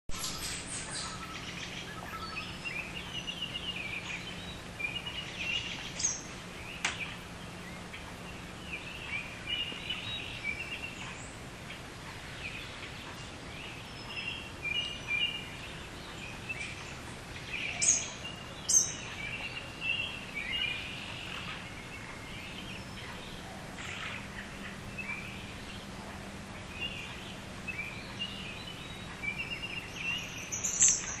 Birds at Dawn (251k, running time 31 sec.)
Pale blue twilight breaks the grip of darkness on the eastern horizon to show you the pond's as still as glass, the spiderweb covered with dew. Every morning, these birds are up before the sun.
birds_dawn.WMA